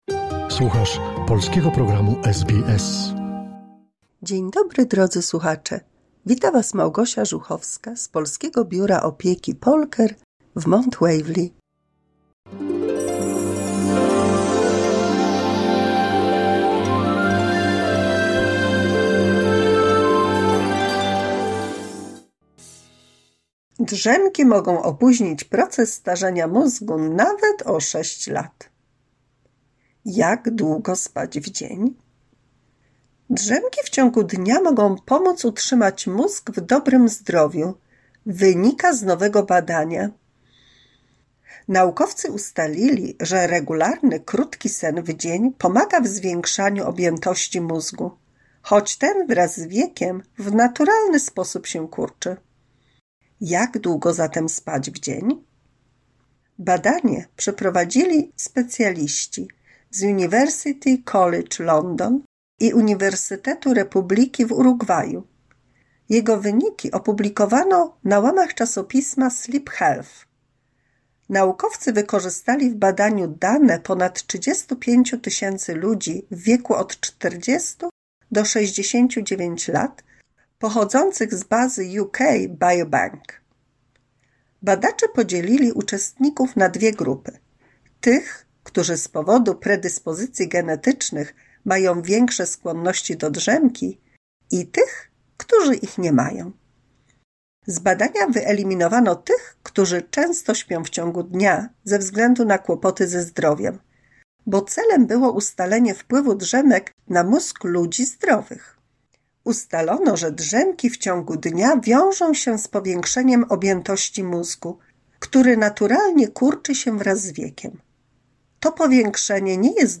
170 mini słuchowisko dla polskich seniorów